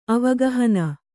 ♪ avagahana